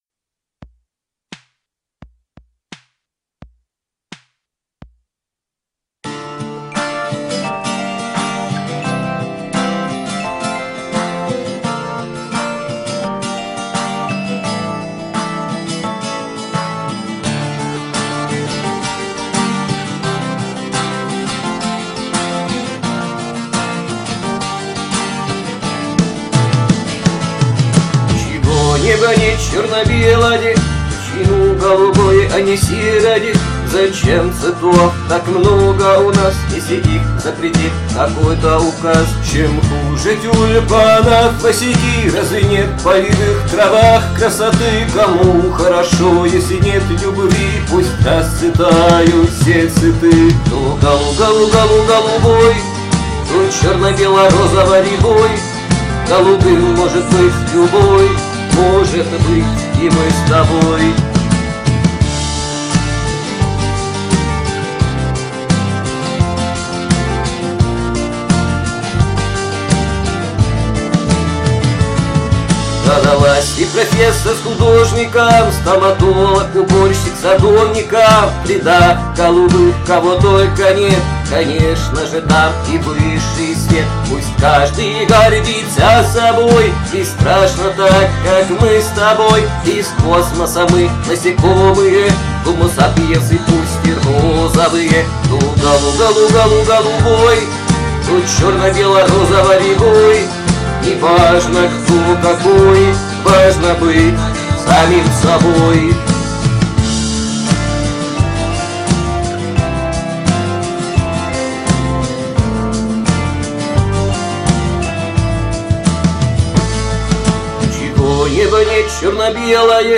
raakademo